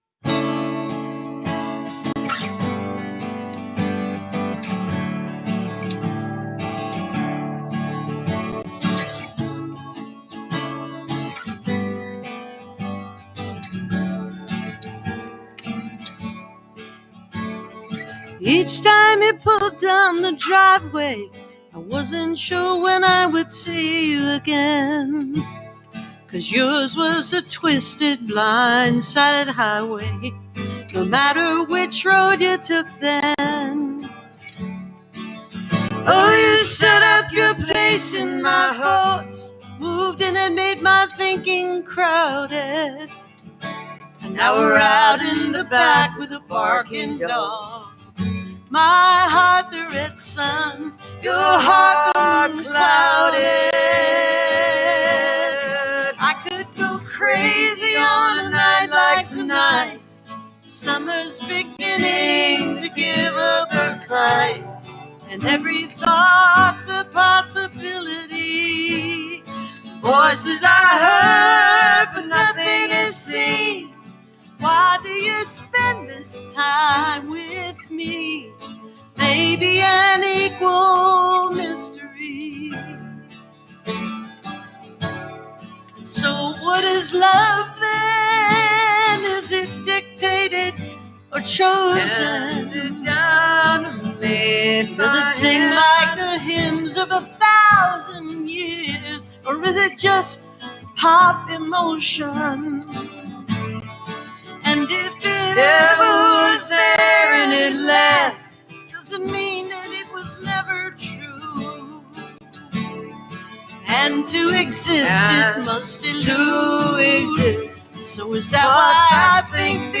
(captured from the youtube livestream)